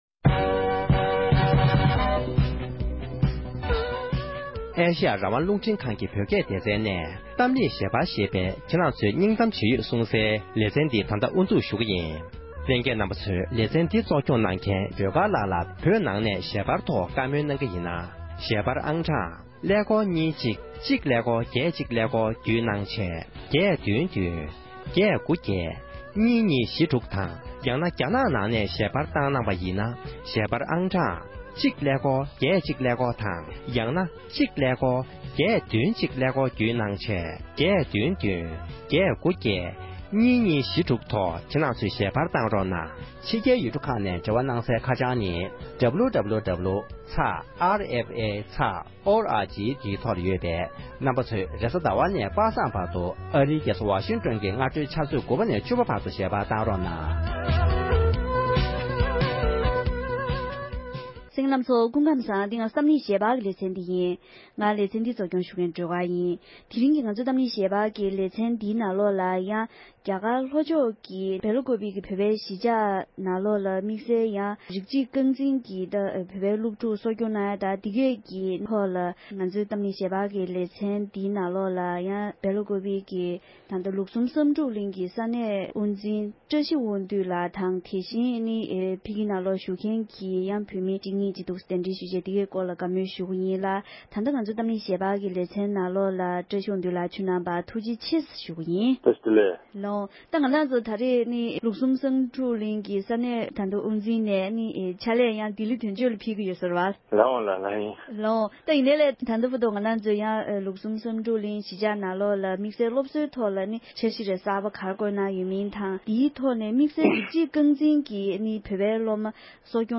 འབྲེལ་ཡོད་མི་སྣའི་ལྷན་བགྲོ་གླེང་ཞུས་པར་གསན་རོགས༎